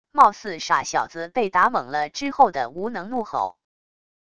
貌似傻小子被打懵了之后的无能怒吼wav音频